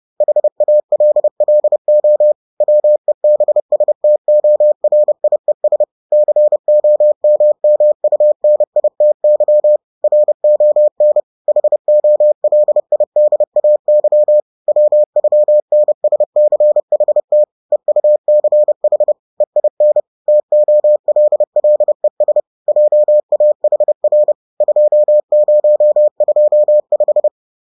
<==== MORSE Grußbotschaft AN ALLE LESER: ICON links anklicken.